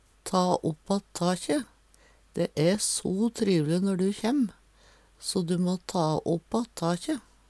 ta opp att takje - Numedalsmål (en-US)